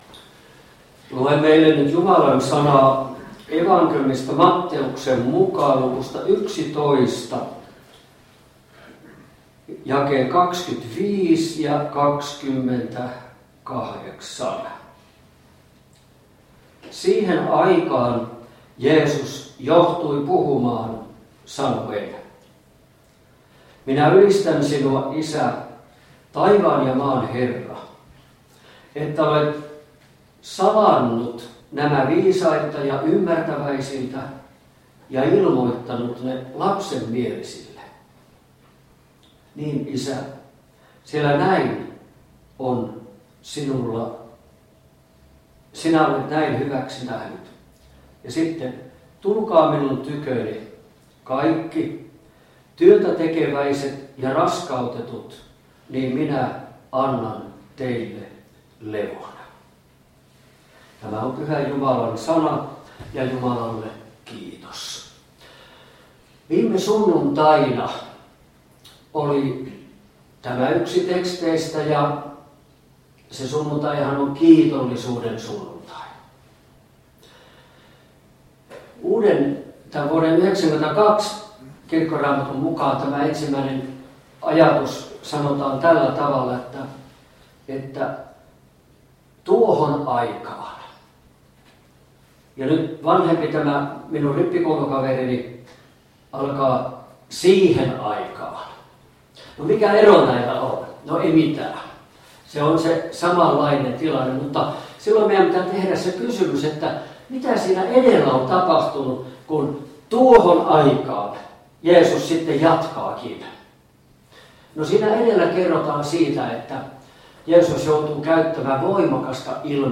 Karstulan evankeliumijuhlassa Tekstinä Matt. 11: 25-28